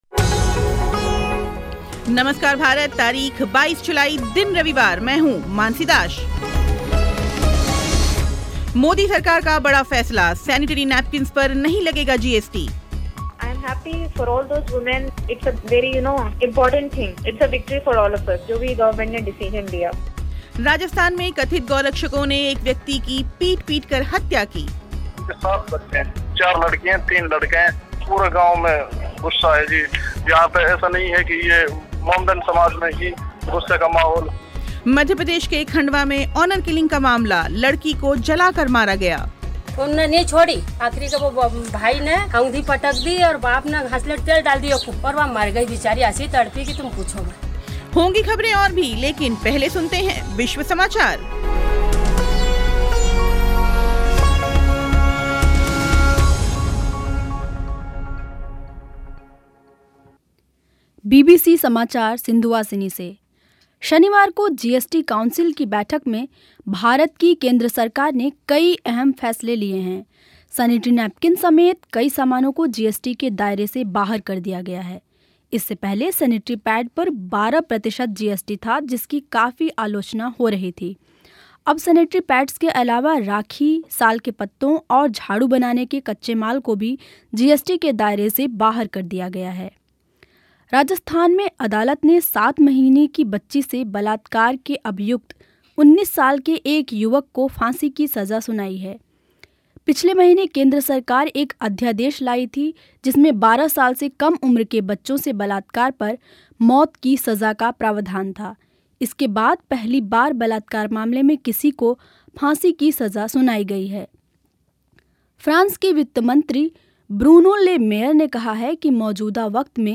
सुनिए कांग्रेस के वरिष्ठ नेता दिग्विजय सिंह के साथ ख़ास बातचीत